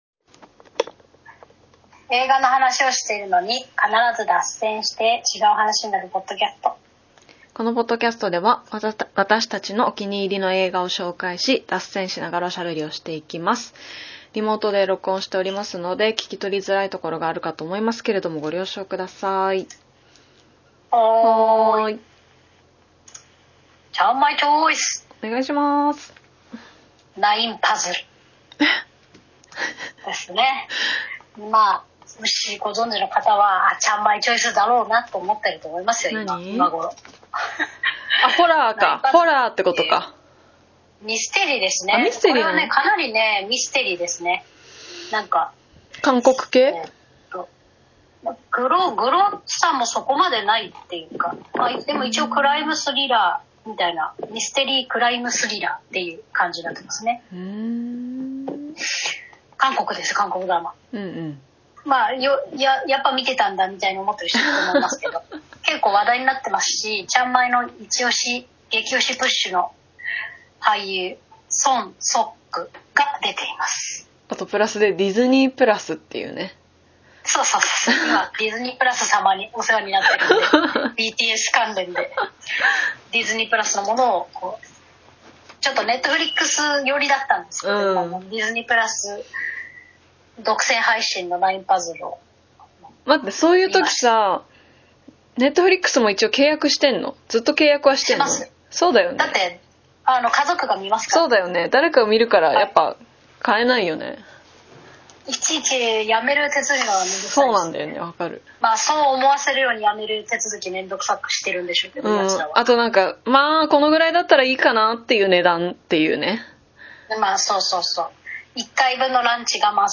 (現在はリモート収録中)毎週月曜日に更新しています。